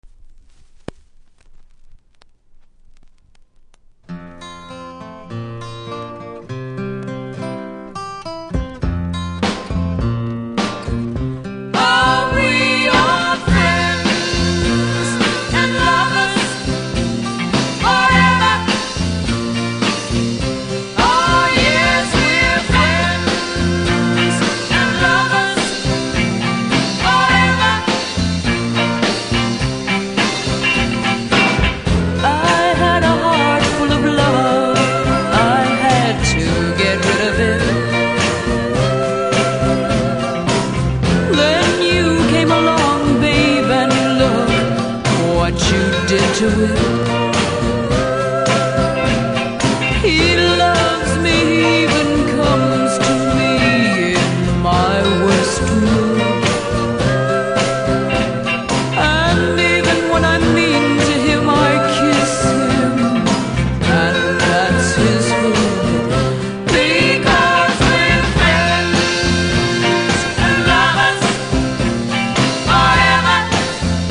うすい擦りキズ多めですが音には影響せず良好です。
無録音部分にノイズありますが音が始まる前なので問題無し。